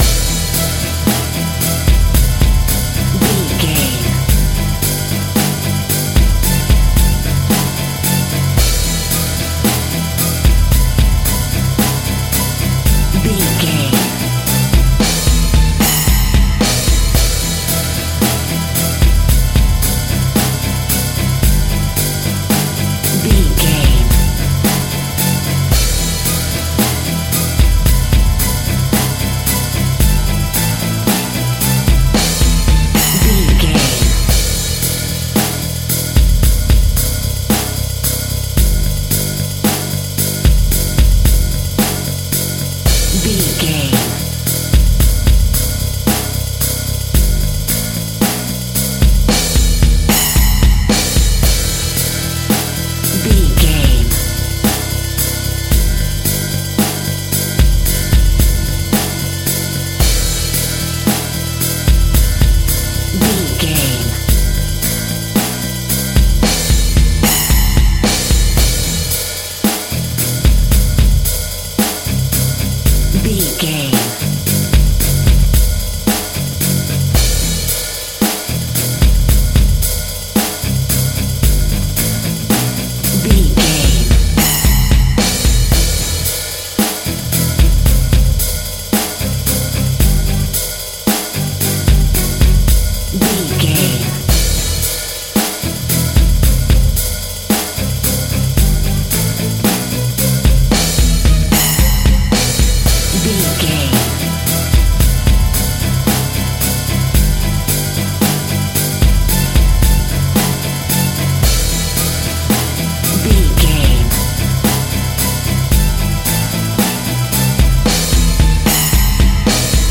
Heavy Horror Metal.
Aeolian/Minor
ominous
dark
eerie
bass guitar
drums
organ
strings
synth